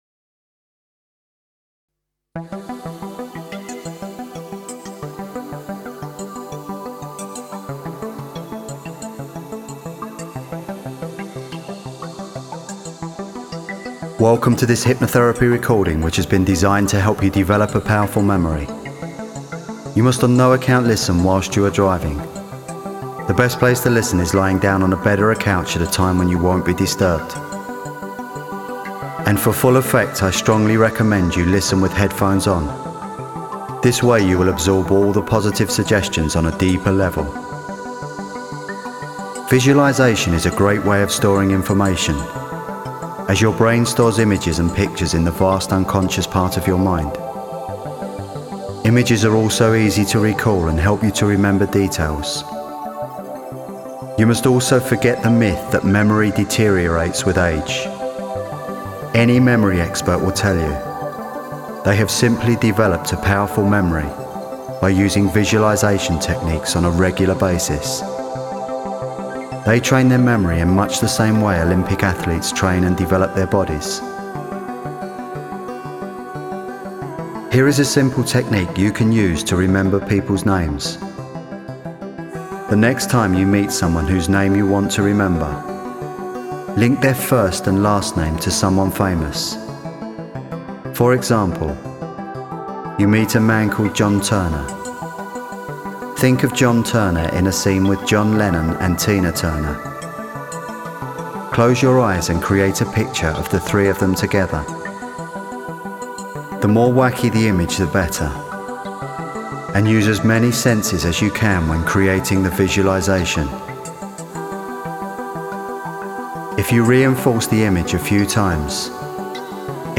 In both hypnotherapy sessions, you will hear a pleasant English voice and absorbing sound effects guiding you into a deeply relaxed state of mental and physical relaxation. In this very receptive, relaxed state, you will be given a number of posthypnotic suggestions and carefully layered affirmations to help you store and recall information more easily.